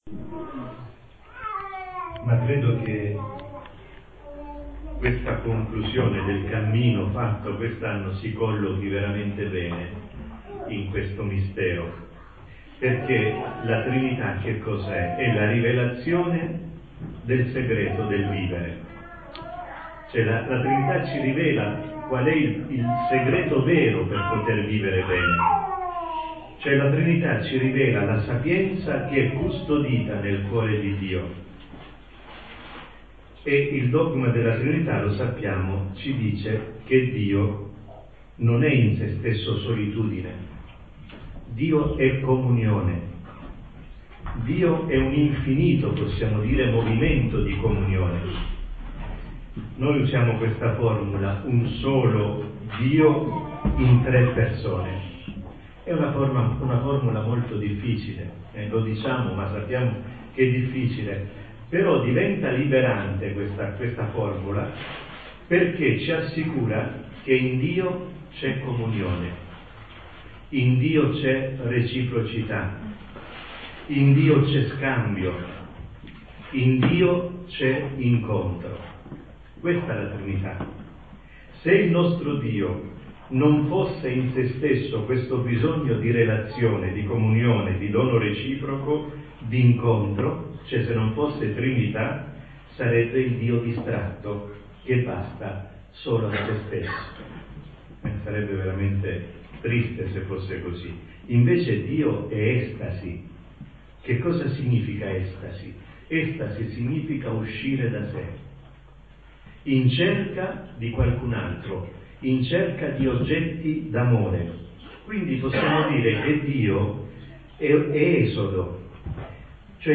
Dic 29, 2011 // di admin // MEDIA , Musica // Nessun commento Incontro Coppie 19 giugno 2011 - Omelia celebrazione di don Angelo DE DONATIS Incontro coppie 19 giugno 2011- Omelia celebrazione di don Angelo DE DONATIS